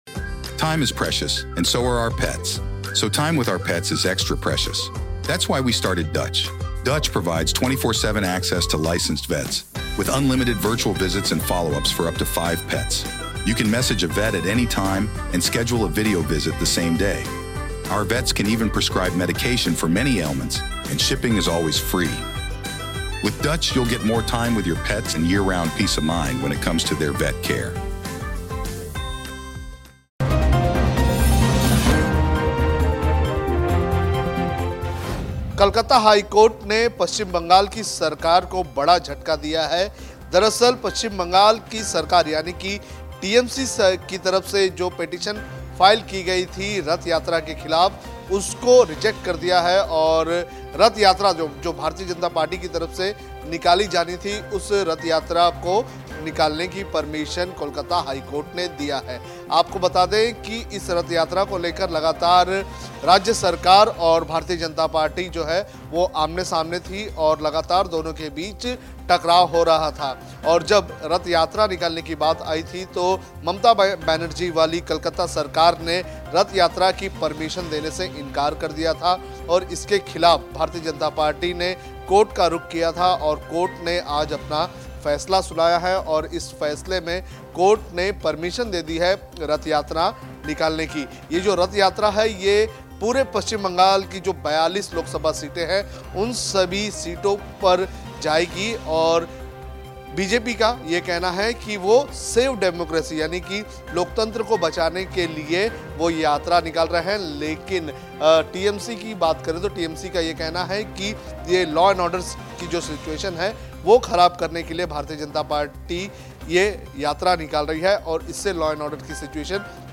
न्यूज़ रिपोर्ट - News Report Hindi / ममता बनर्जी को झटका, कलकत्ता हाई कोर्ट ने दी बीजेपी को रथ यात्रा की इजाज़त